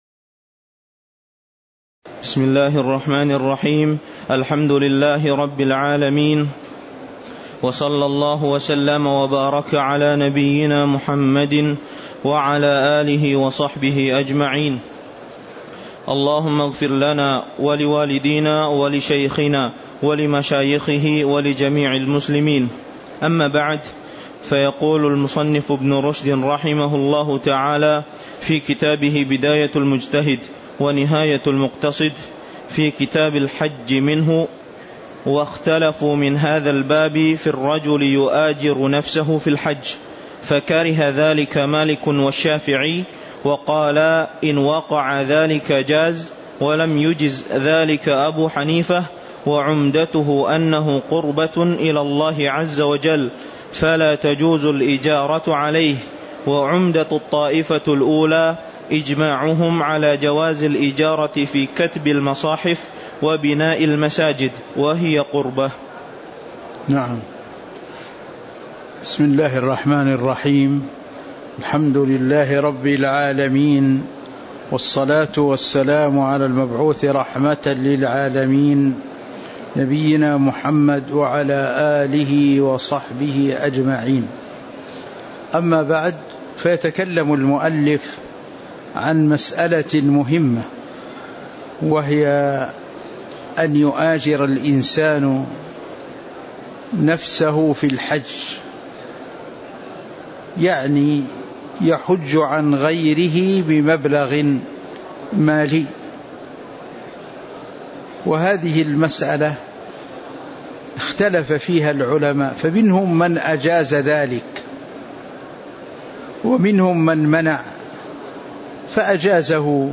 تاريخ النشر ٢٢ ذو القعدة ١٤٤٤ هـ المكان: المسجد النبوي الشيخ